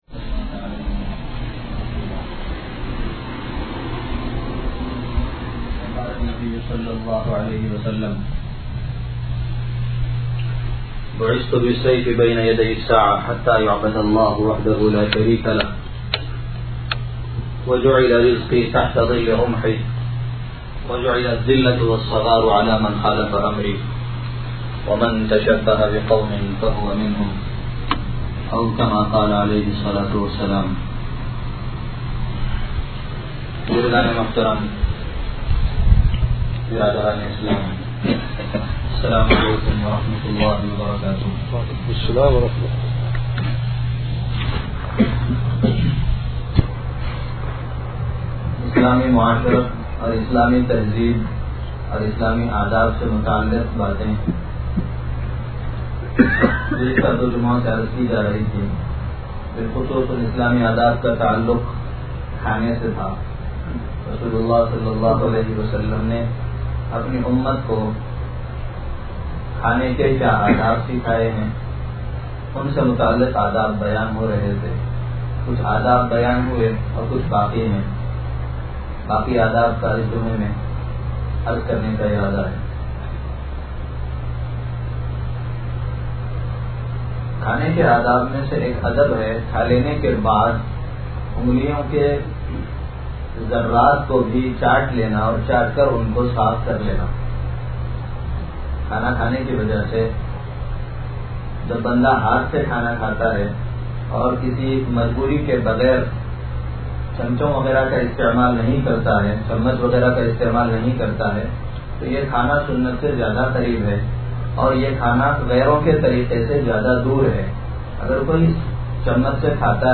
(Jum'ah Bayan)